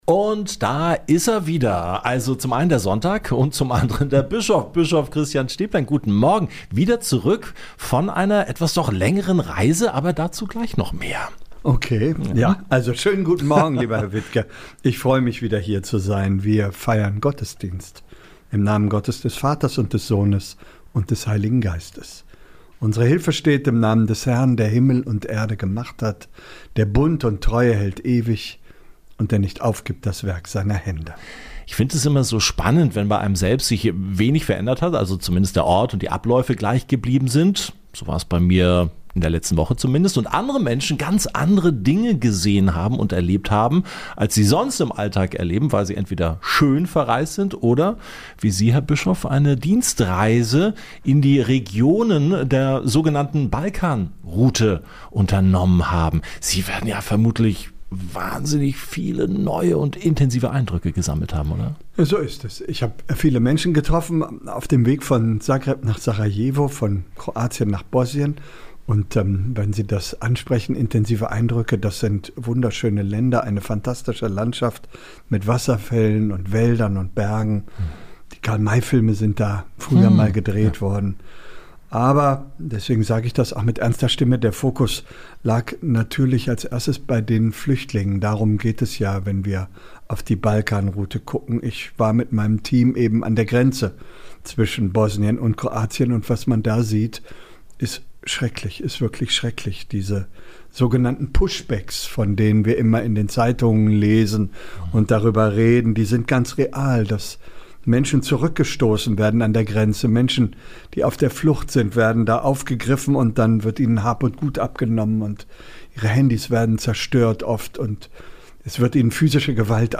Bischof Stäblein ist in seiner Funktion als Flüchtlingsbischof der EKD in die Regionen der "Balkanroute" gereist. Von seinen Eindrücken und Begegnungen mit den Menschen, die sich dort auf der Flucht befinden, erzählt auch in diesem Gottesdienst im Gespräch.